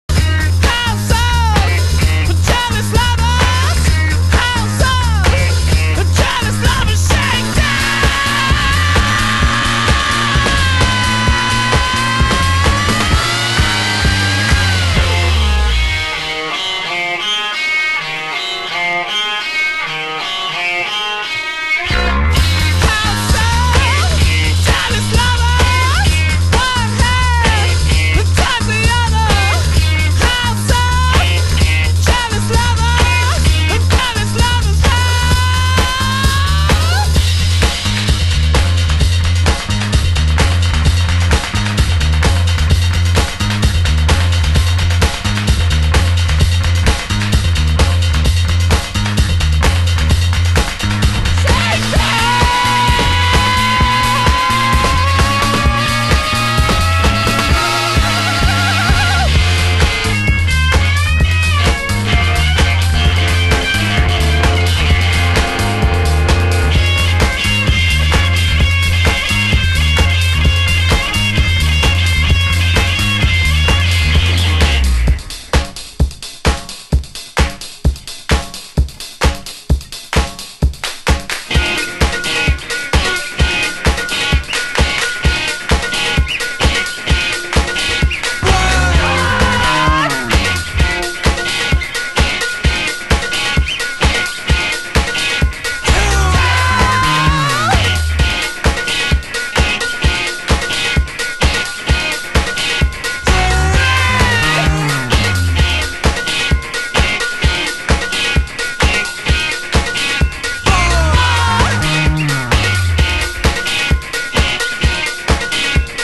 盤質：軽いスレ、少しチリパチノイズ有